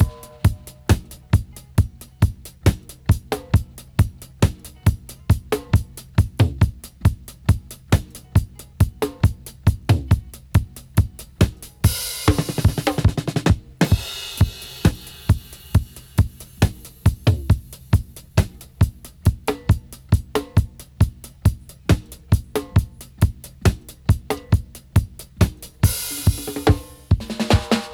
136-DRY-02.wav